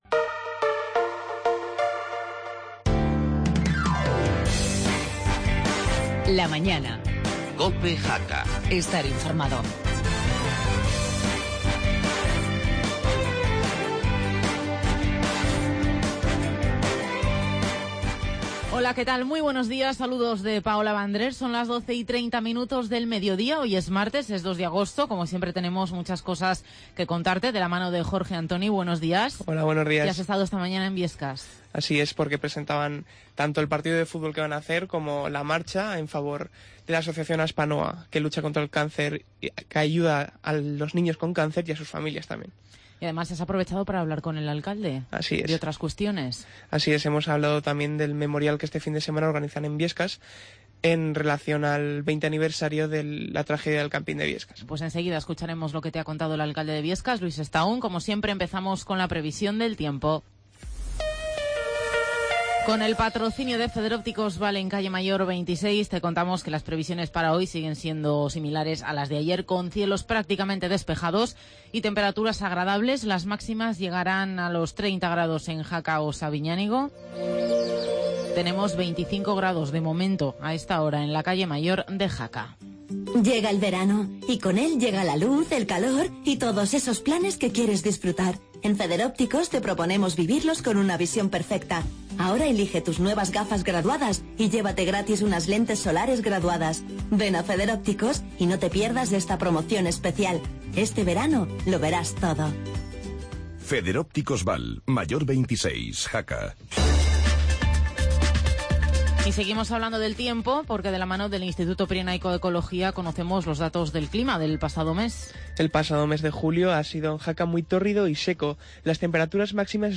Redacción digital Madrid - Publicado el 02 ago 2016, 14:32 - Actualizado 02 oct 2018, 17:58 1 min lectura Descargar Facebook Twitter Whatsapp Telegram Enviar por email Copiar enlace Toda la actualidad de la Jacetania y el Alto Gállego. Además hablamos con los alcaldes de Biescas y Villanúa, sobre lo que está ocurriendo en sus localidades.